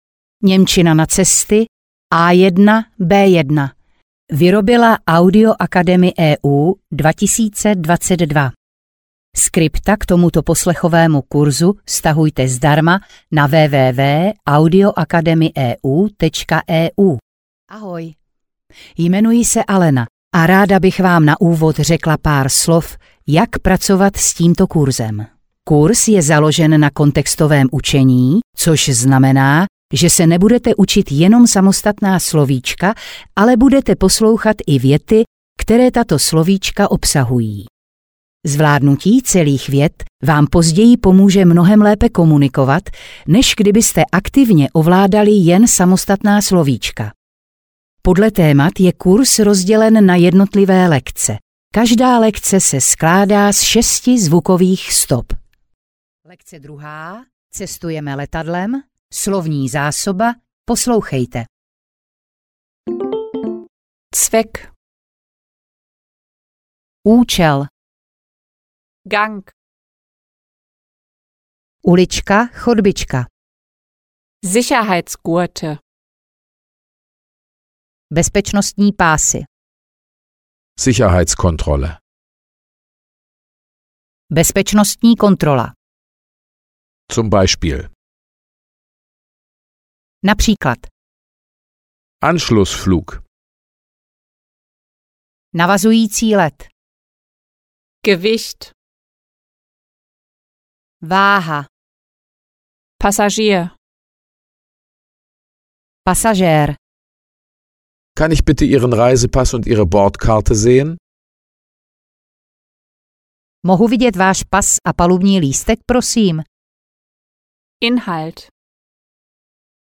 Němčina na cesty A1-B1 audiokniha
Ukázka z knihy
Dále máte k dispozici slovíčko následované příkladovou větou, opět v obou variantách překladu (stopa 3 a 4).